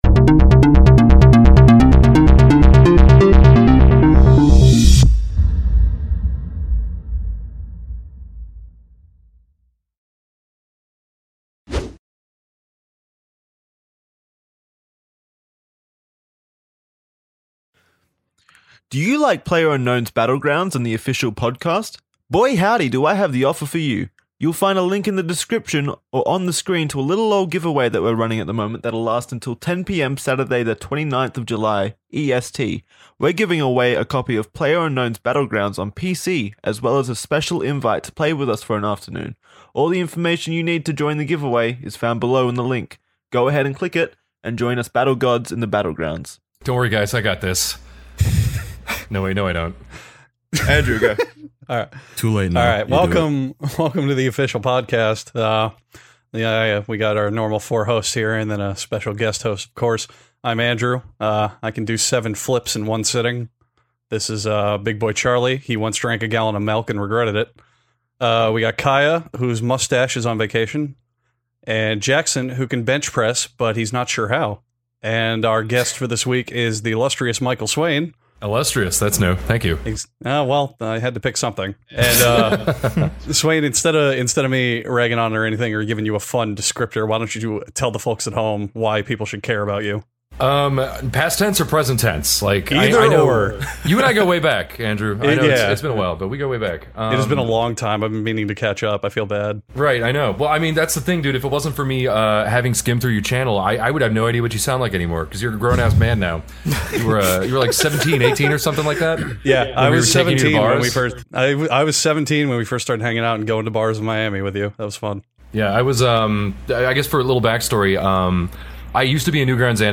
Five close man friends gather around to talk about chads.